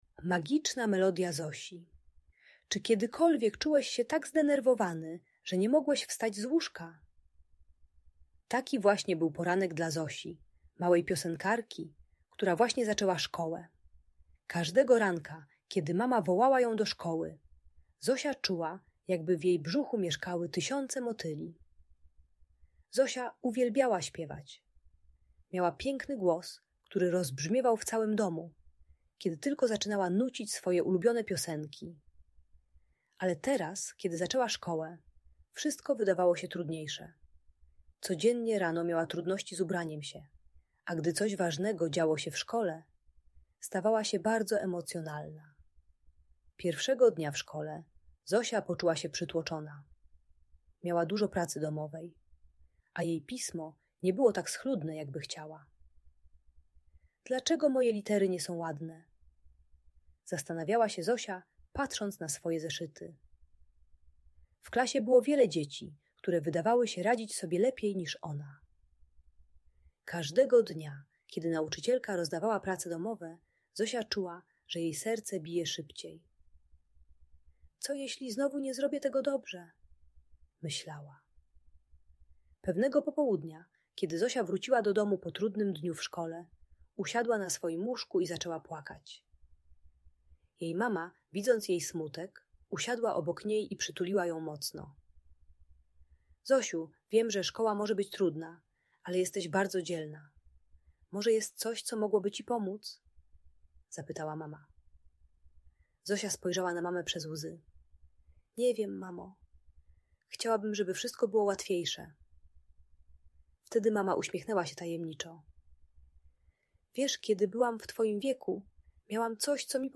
Magiczna Melodia Zosi - Szkoła | Audiobajka